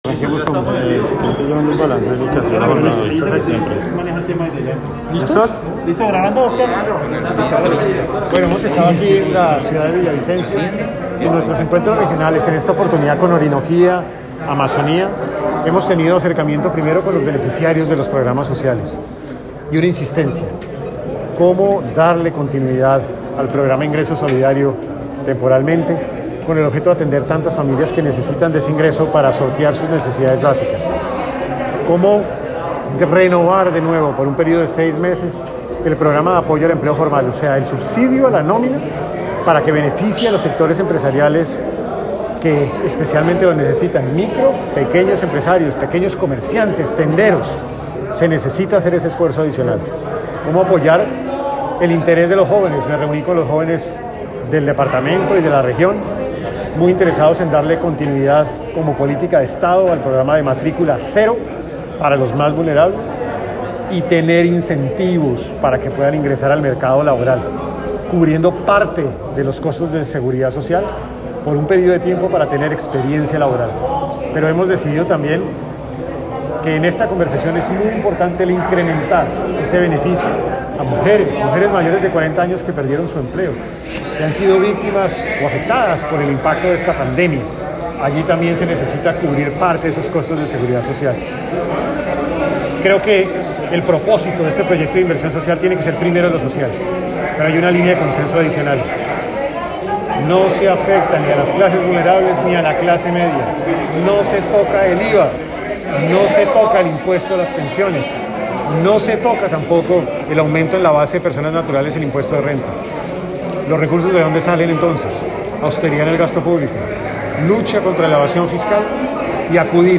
Declaraciones del Ministro en la jornada en Villavicencio